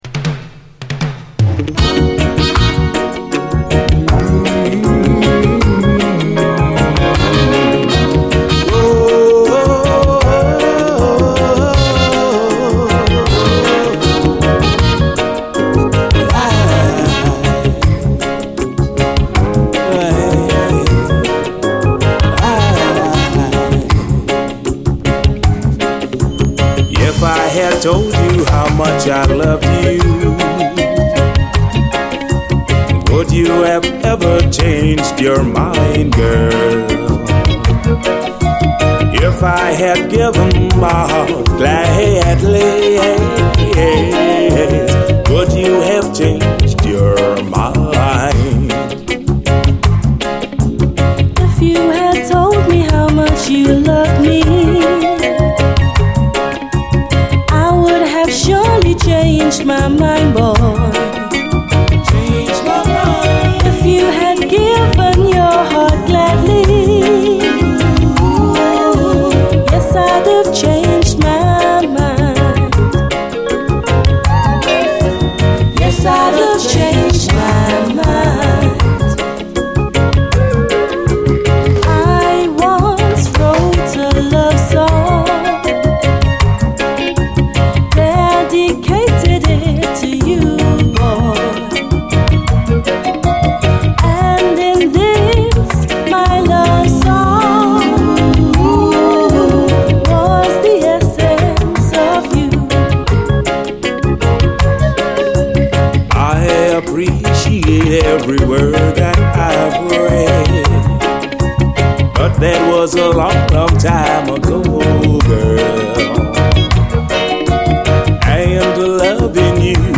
REGGAE
詳細不明のマイナー89年UKラヴァーズ！
ラベルに書き込み/　男女デュエットによる詳細不明のマイナー89年UKラヴァーズ！ライトなナイス・チューン！